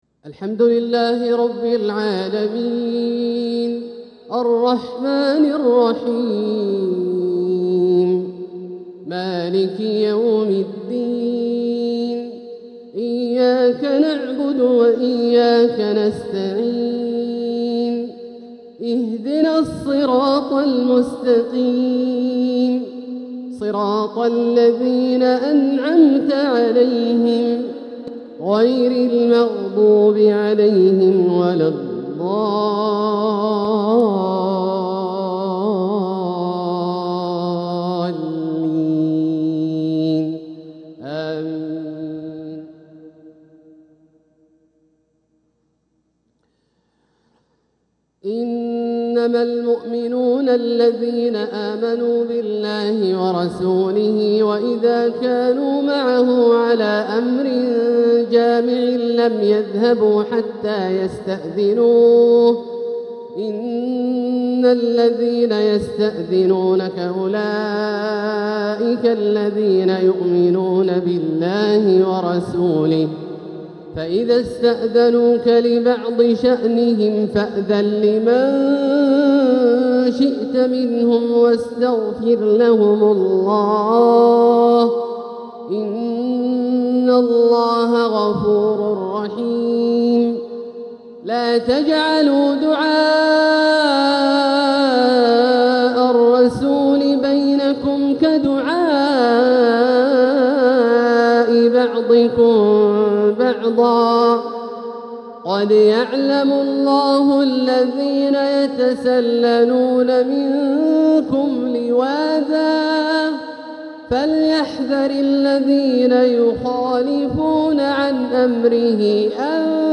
تلاوة من سورة النور و الكوثر | مغرب الخميس 13 صفر 1447هـ > ١٤٤٧هـ > الفروض - تلاوات عبدالله الجهني